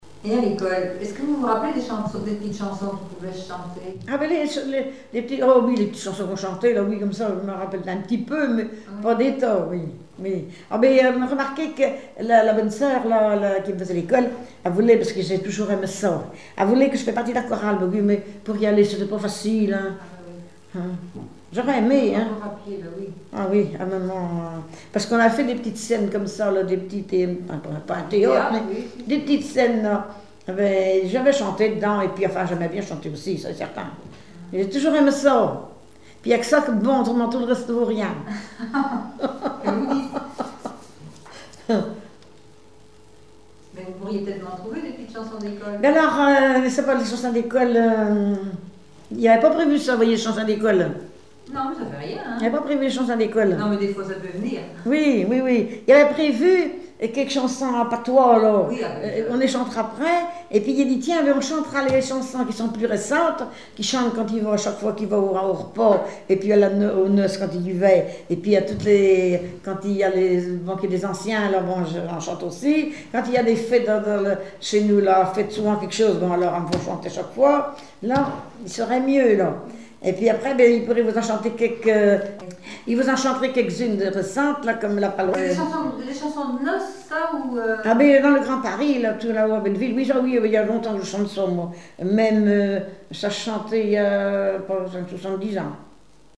Chansons et témoignages
Catégorie Témoignage